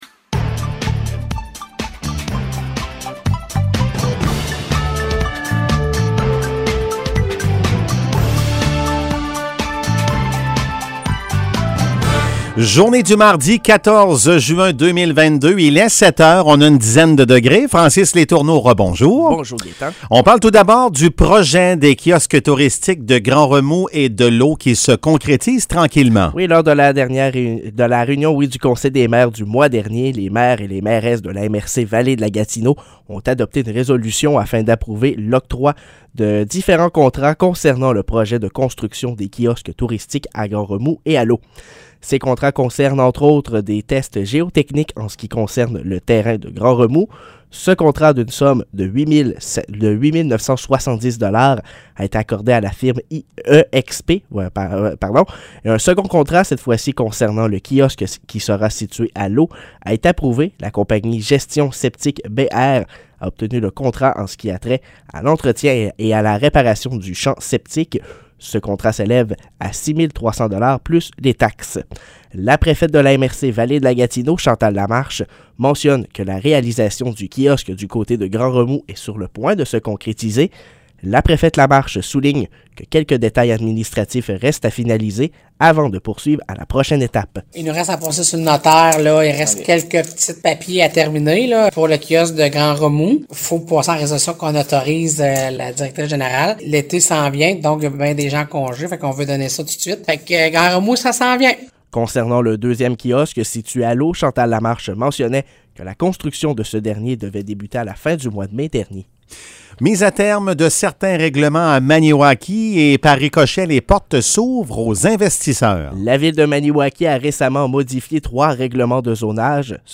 Nouvelles locales - 14 juin 2022 - 7 h